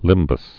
(lĭmbəs)